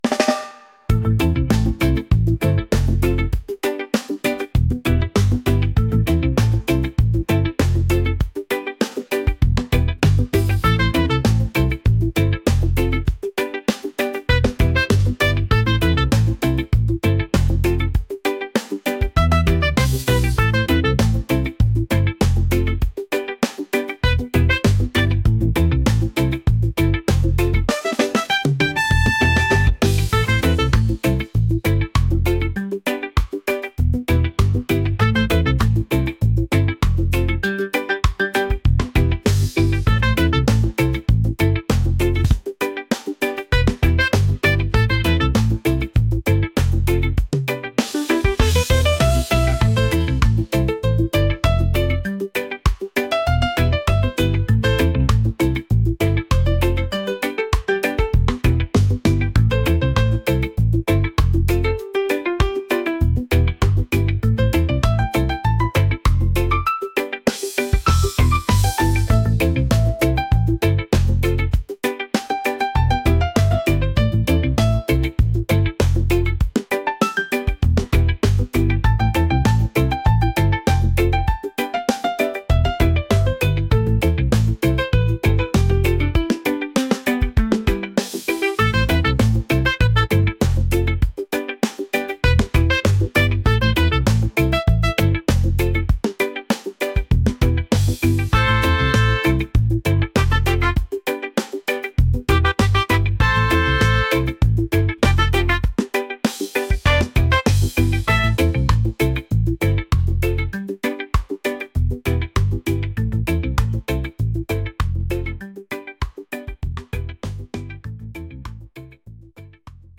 reggae | fusion | groovy